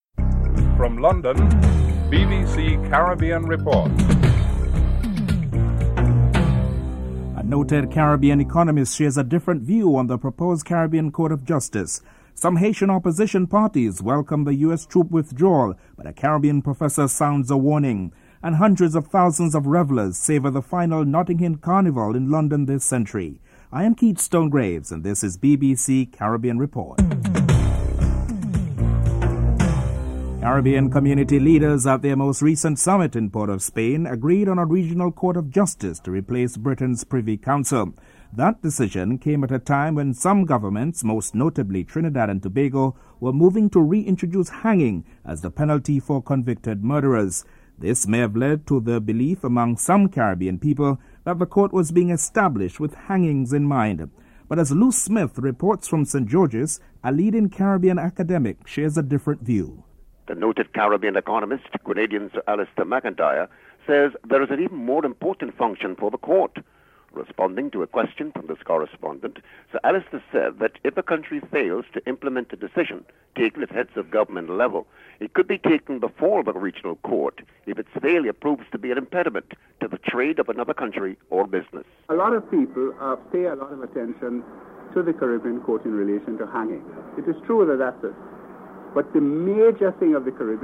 Caribbean community leaders at a Summit in Port of Spain, Trinidad and Tobago reached a consensus to establish a Caribbean Court of Justice to replace Britain’s Privy Council. A leading Caribbean economist and academic Grenadian Alister McIntyre shares his views on the establishment of the Caribbean Court and adjudication regarding the death penalty.